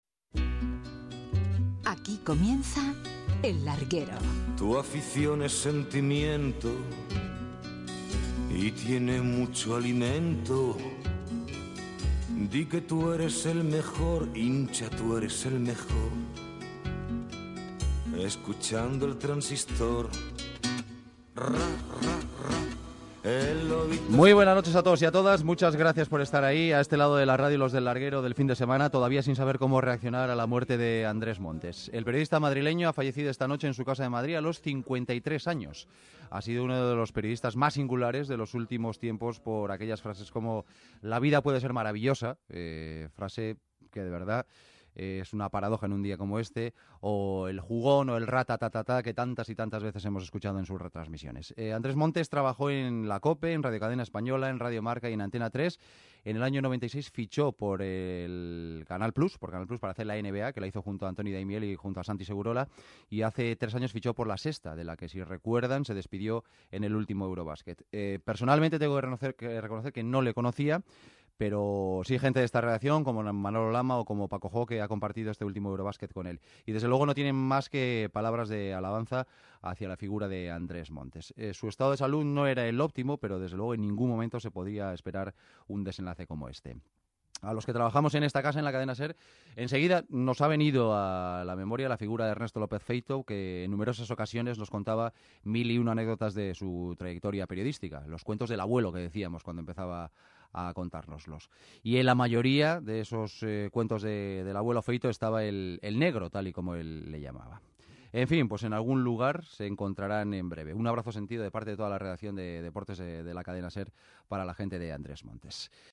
Careta del programa, record a la figura del comentarista esportiu Andrés Montes que havia mort aquell dia
Esportiu